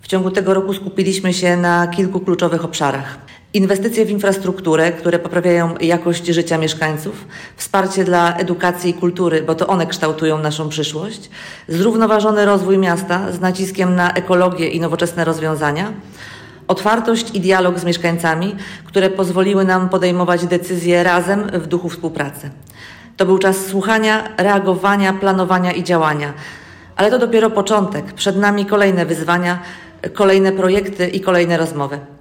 Wiceprezydent Koszalina, Beata Górecka, wskazuje, że mijające miesiące to czas dynamicznych działań w wielu obszarach: